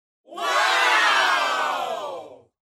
众人哇呜音效_人物音效音效配乐_免费素材下载_提案神器
众人哇呜音效免费音频素材下载